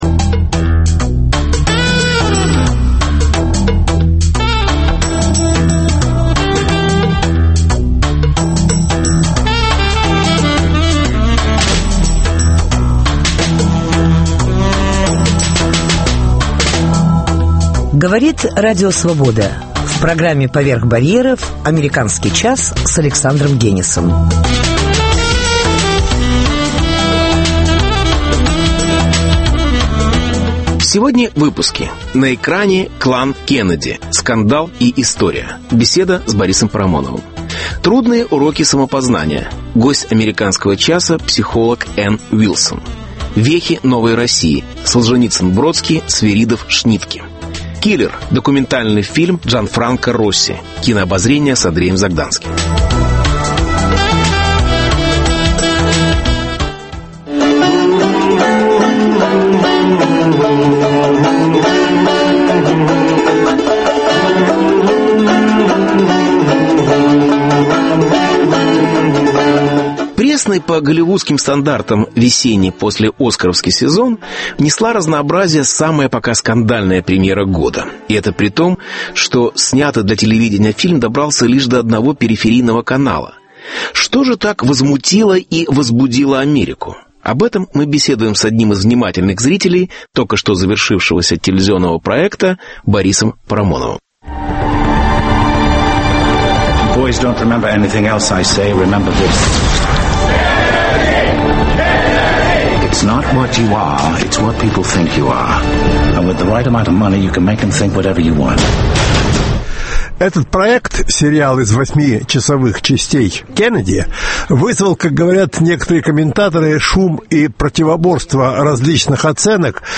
На экране клан – ‘Кеннеди’: скандал и история? Беседа с Борисом Парамоновым.